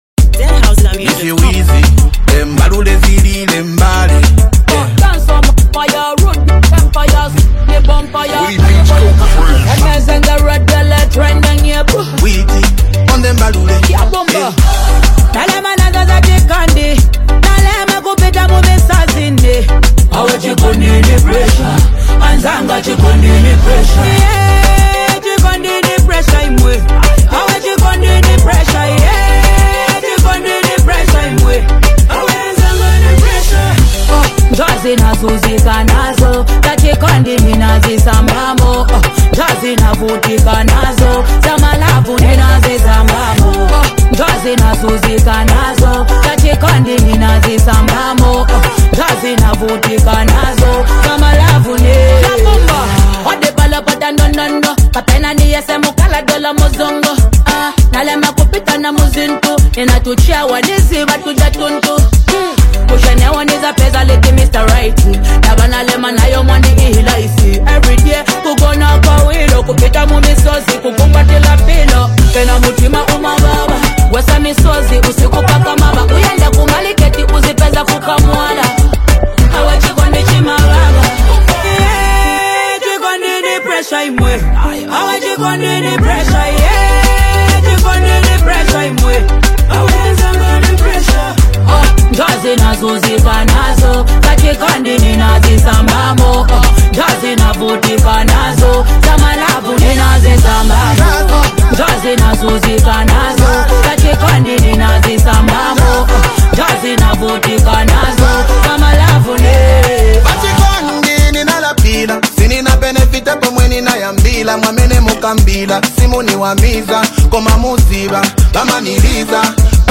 a vibey mix of confidence, class, and catchy lyrics.